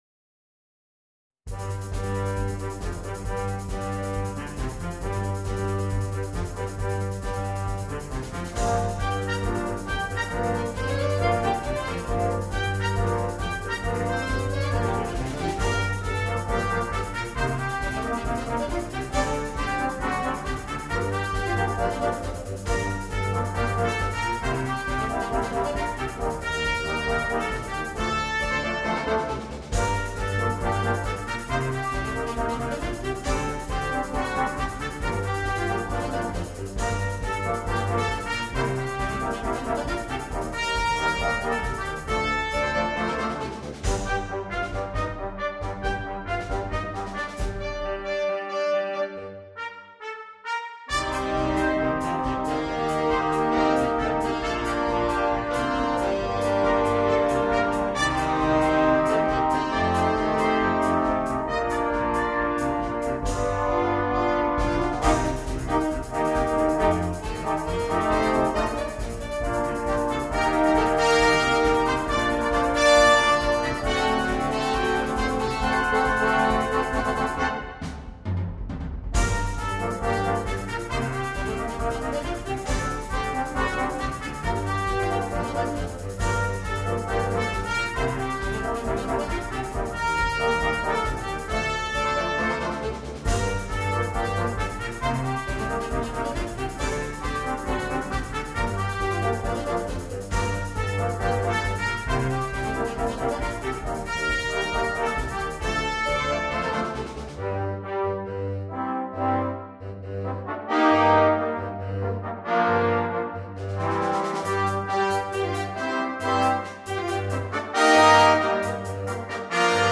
для биг-бэнда